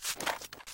Divergent / mods / Footsies / gamedata / sounds / material / human / step / grass1.ogg
grass1.ogg